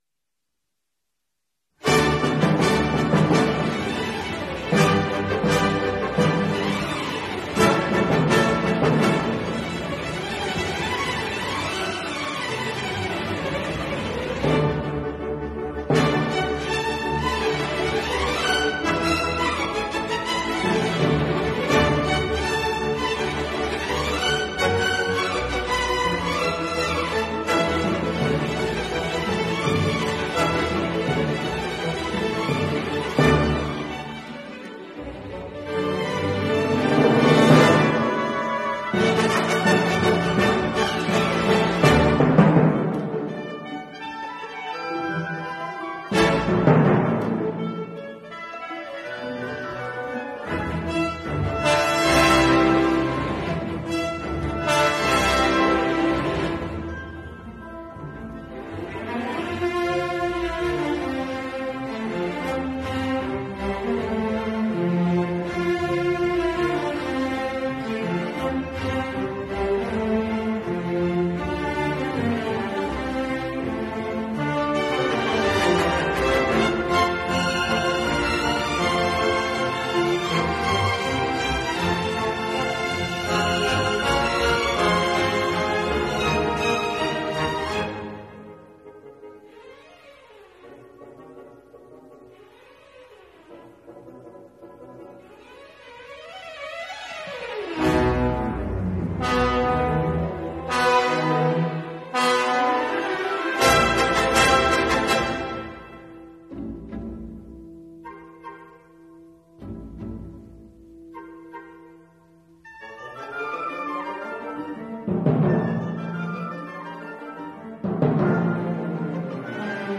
This week we will enjoy a programme of “Sundowner” music. Easy listening for the end of the day.